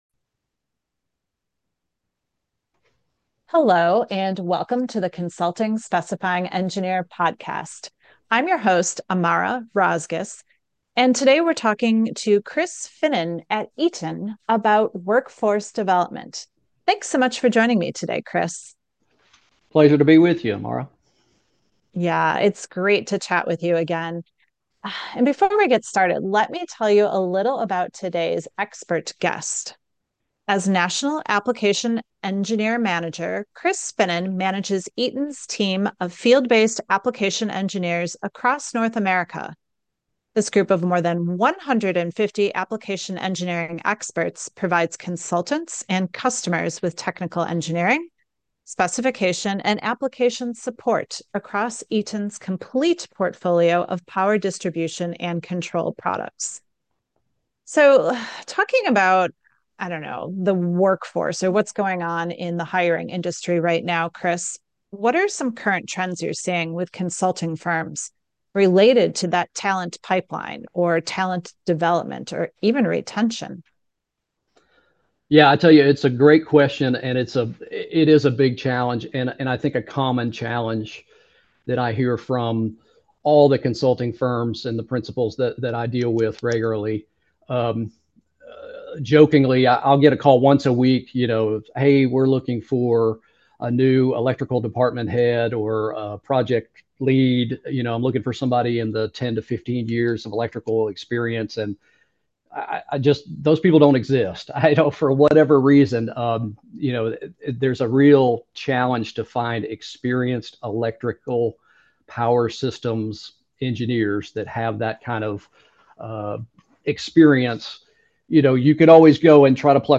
This conversation will give you some tips and tools to help your younger engineers gain practical know-how.